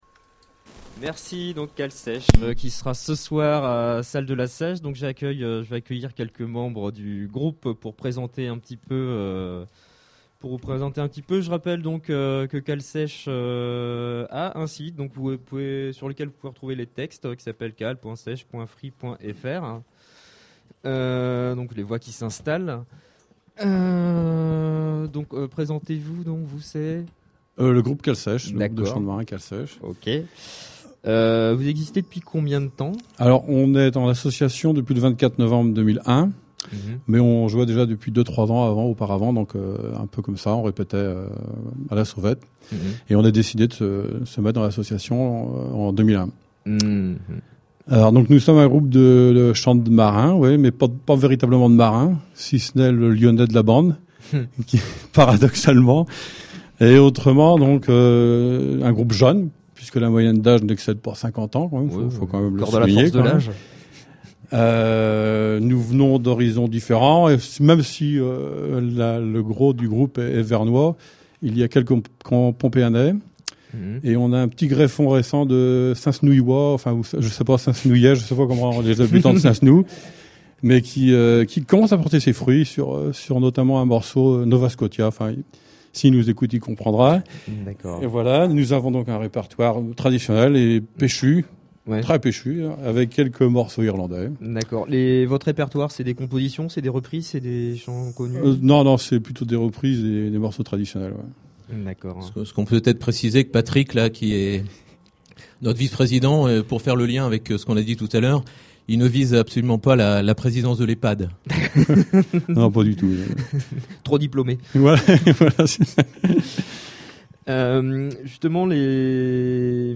Interview + Polka